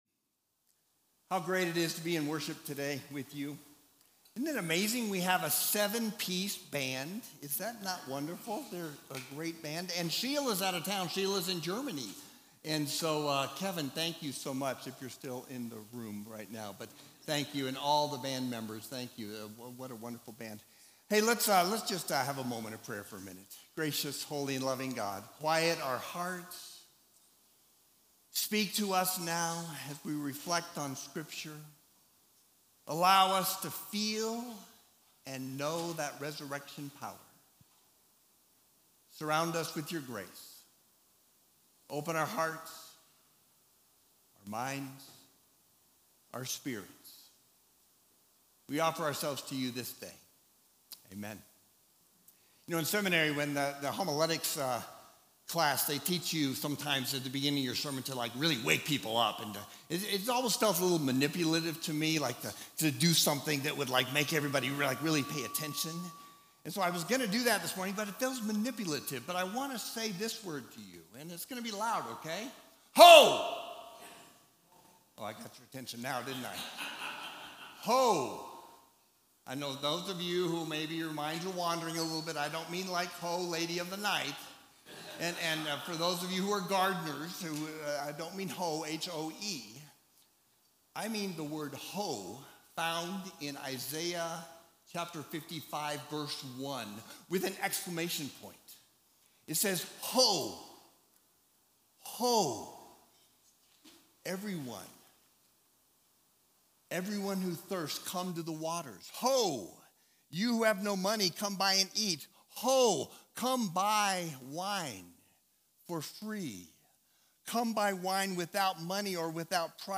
Sermon Series: Holy Living - Mortify